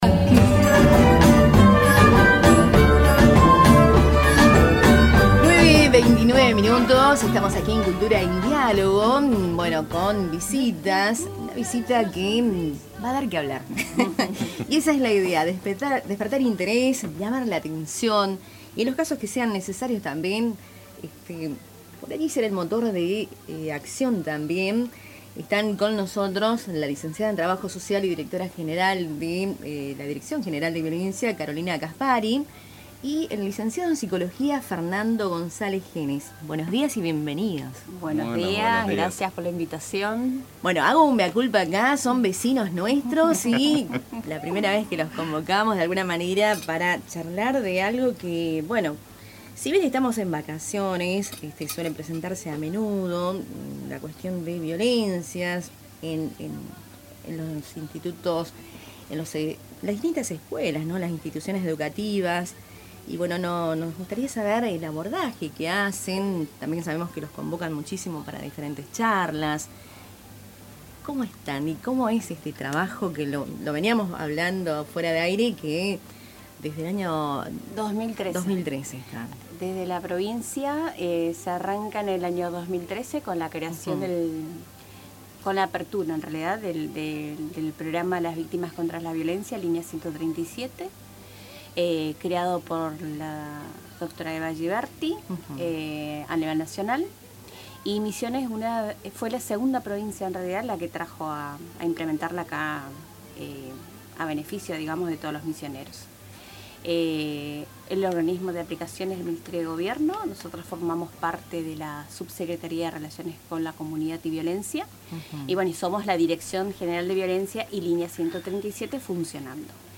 Escuchá la entrevista, realizada en Tupambaé: